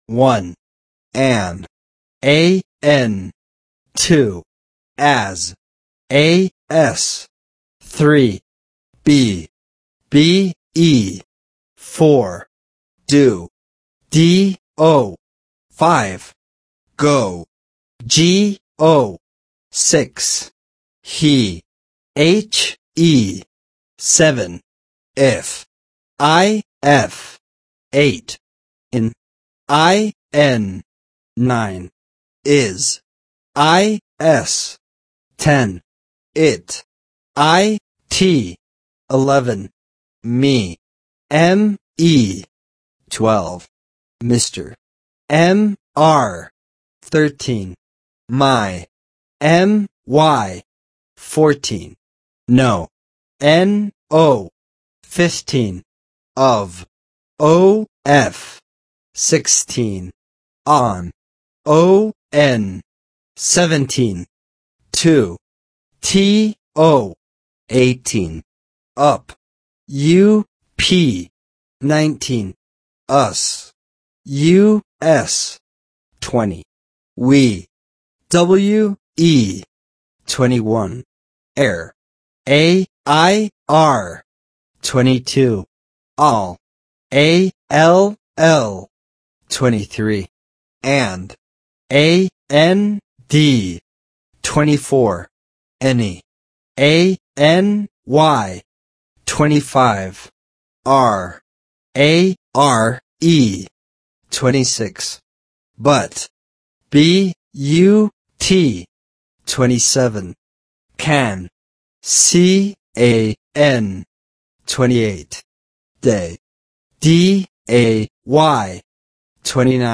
Spelling Exercises
1-50-spelling-words.mp3